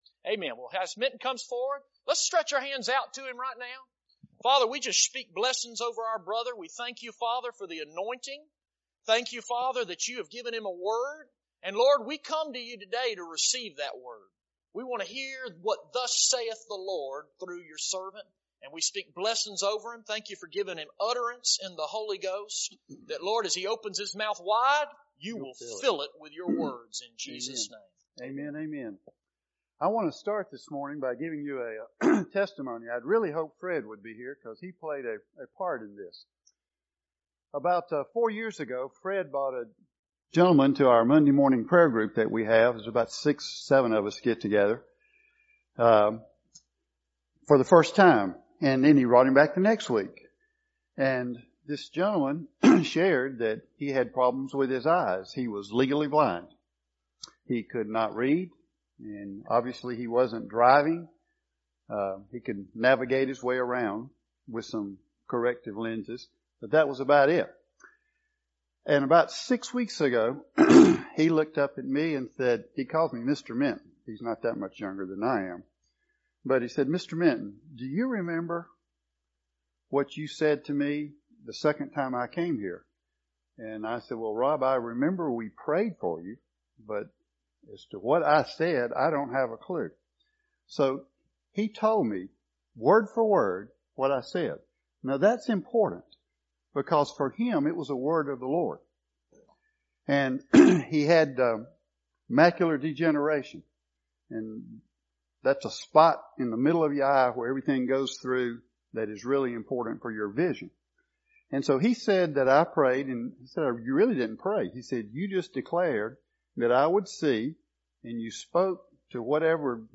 High Point Church, Healing School, Macon, GA